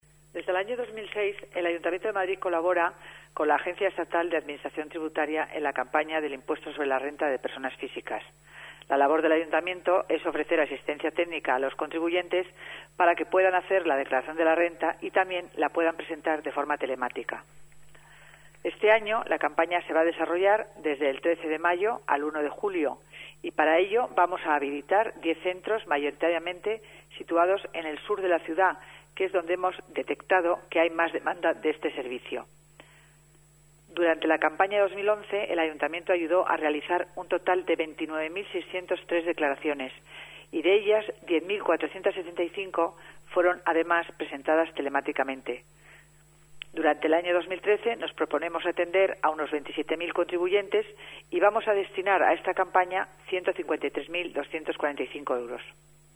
Nueva ventana:Declaraciones de la teniente de alcalde y delegada de Economía, Hacienda y Administración Pública, Concepción Dancausa